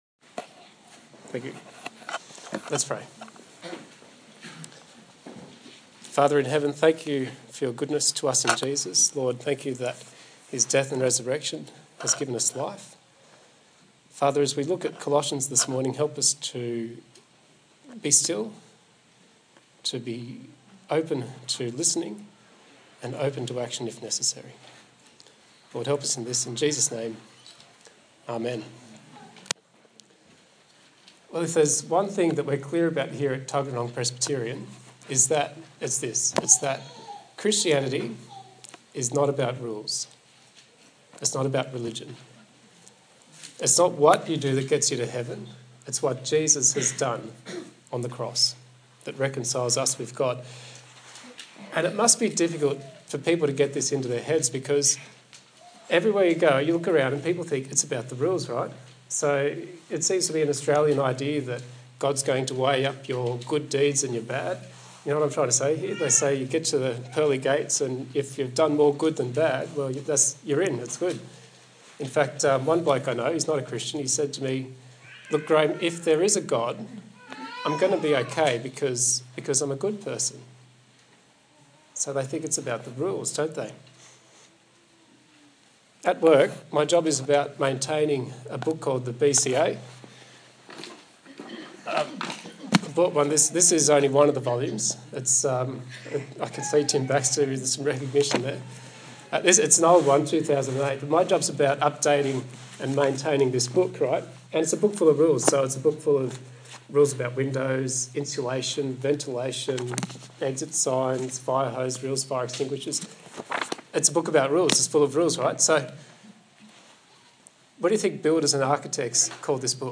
Colossians Passage: Colossians 3:12-17 Service Type: Sunday Morning